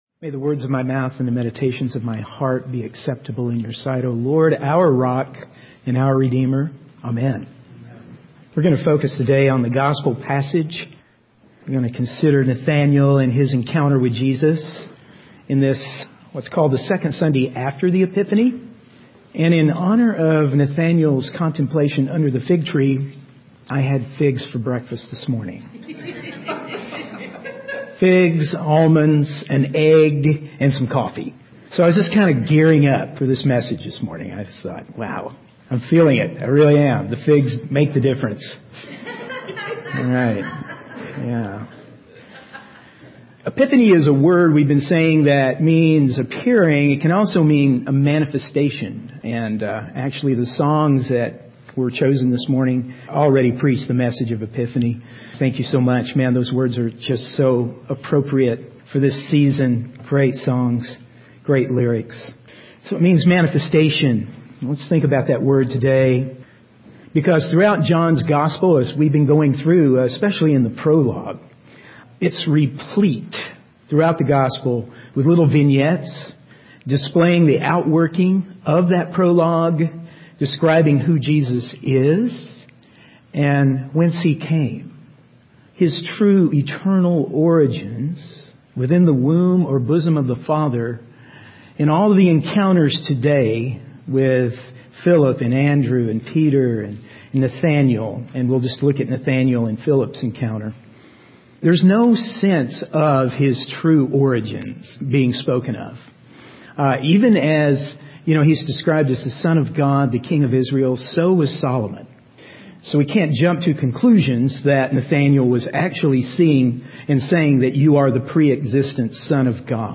In this sermon, the speaker reflects on the encounter between Jesus and Nathaniel. The speaker emphasizes the importance of seeking and experiencing the light of God through Jesus Christ. Salvation is described as the restoration of communion between God and his creation, healing the separation between grace and nature.